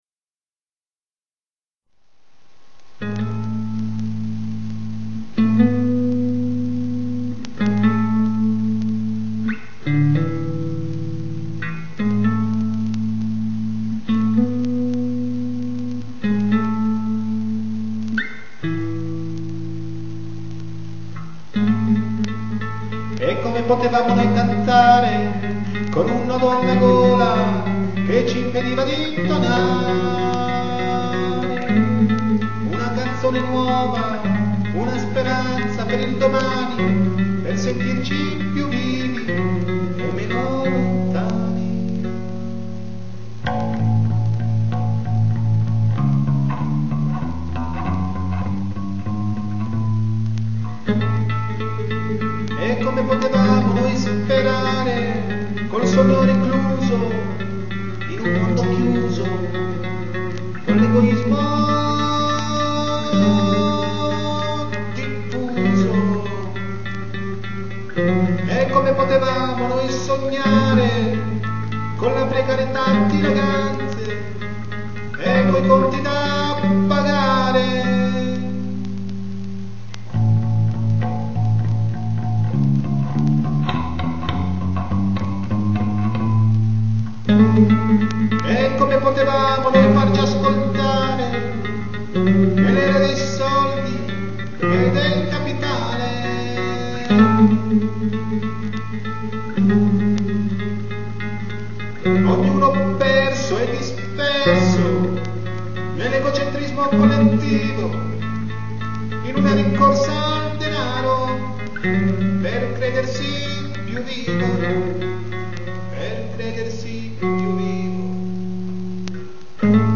Luogo esecuzioneBologna